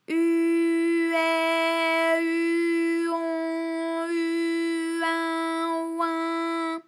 ALYS-DB-001-FRA - First, previously private, UTAU French vocal library of ALYS
u_ai_u_on_u_in_oin.wav